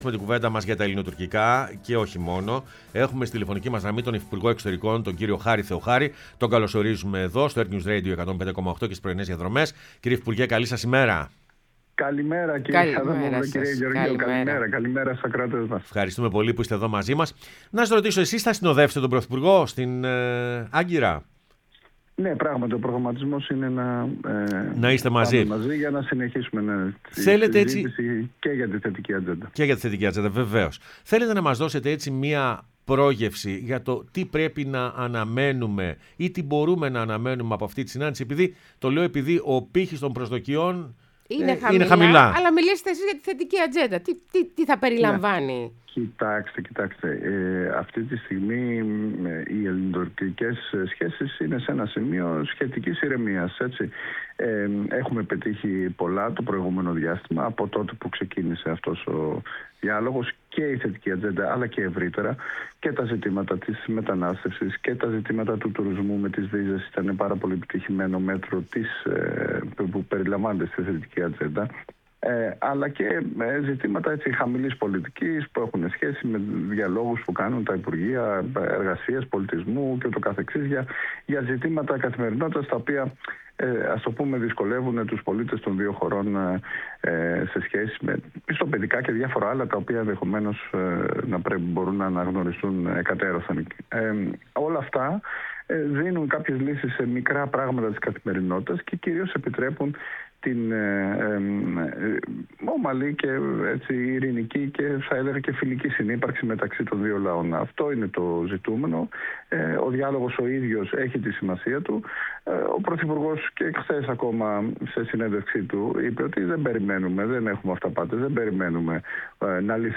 Χάρης Θεοχάρης, Υφυπουργός Εξωτερικών, μίλησε στην εκπομπή «Πρωινές Διαδρομές»